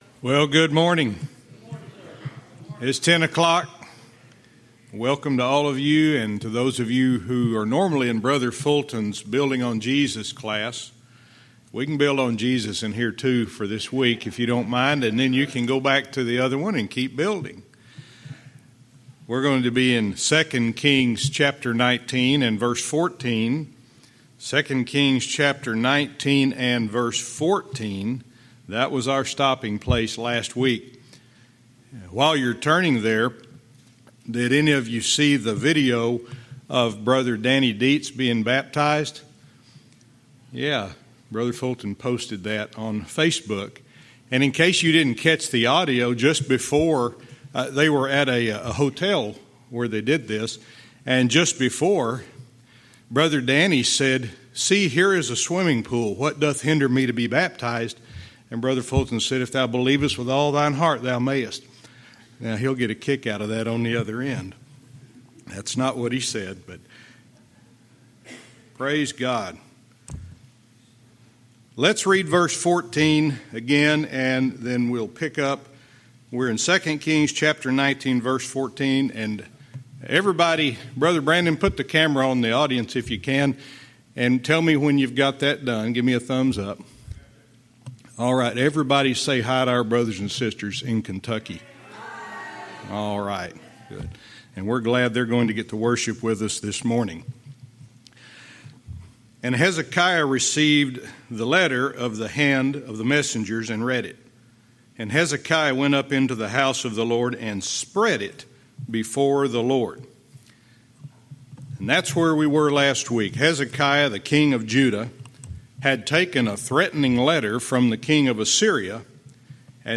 Verse by verse teaching - 2 Kings 19:14-15